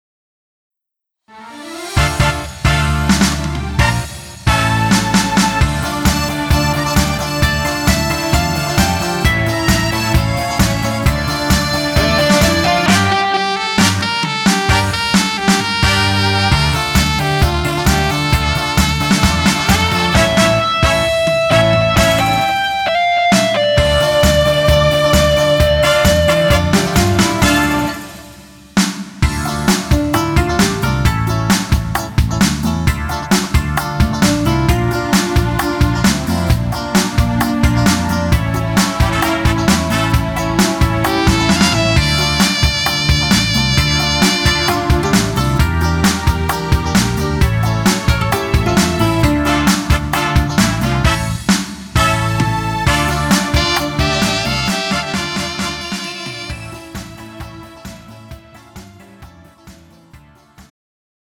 음정 원키 3:27
장르 가요 구분 Pro MR